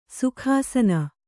♪ sukhāsava